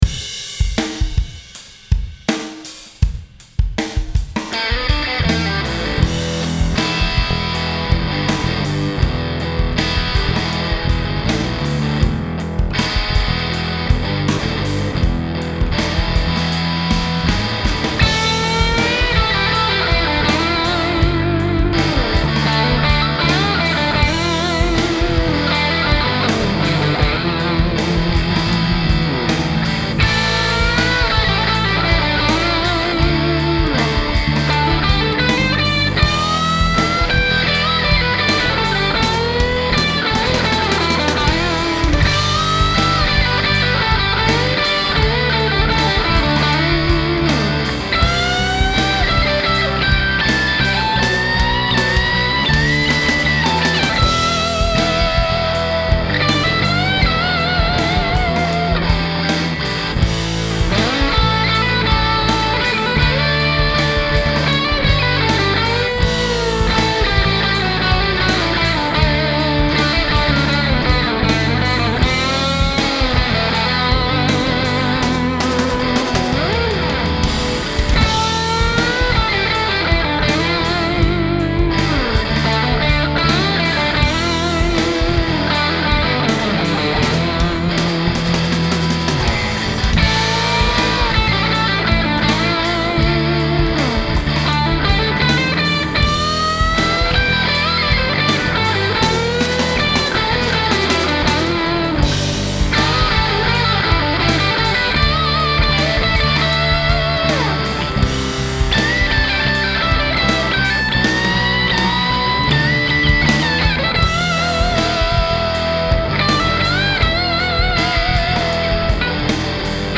It’s such a versatile little amp, with bright cleans and capable of creating some gorgeous, sustaining overdrive.
As far as the amp setting were concerned, I was plugged into the hi input with the amp set to high-power mode. Tone and volume were at 3pm, and I engaged the Boost. Even at these cranked settings, the amp will clean up very nicely!